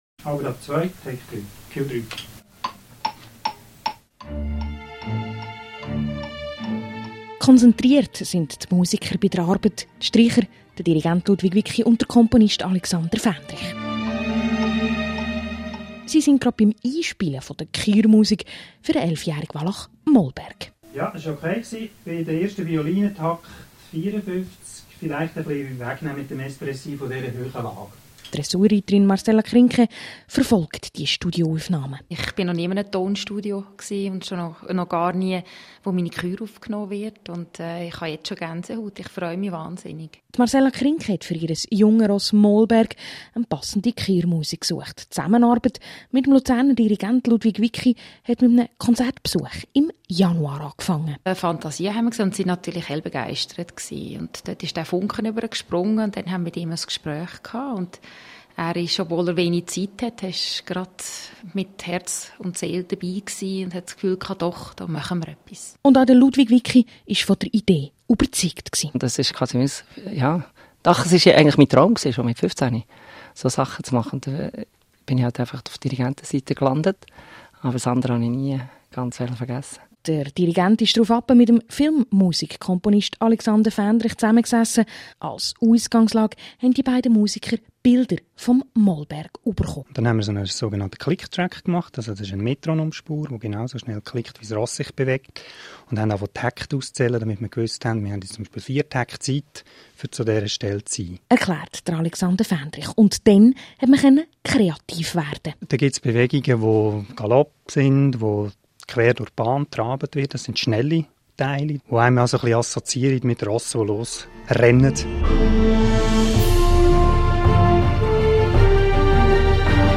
Am Sonntag, 24. Juni wurde ein Beitrag auf DRS 1 und DRS 3 sowie am 26. Juni im Regionaljournal von Radio DRS zur Kürmusik gesendet.